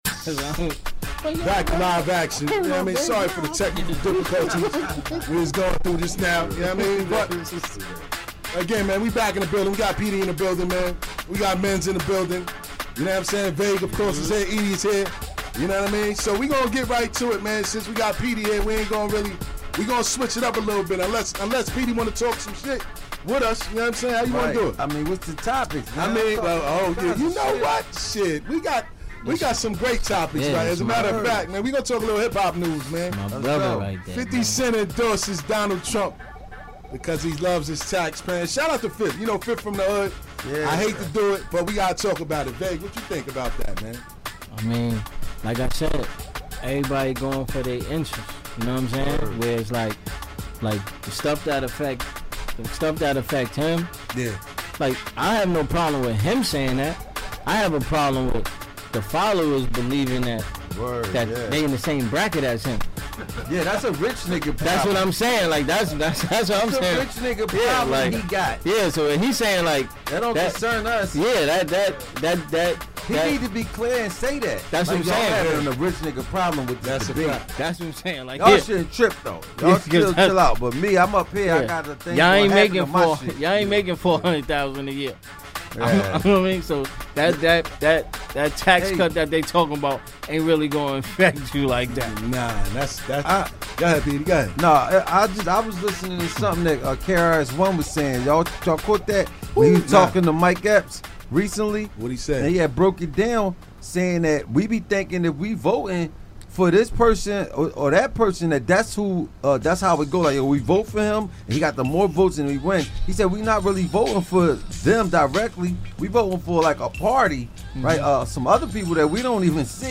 *The Oddcast Peedi Crakk Interview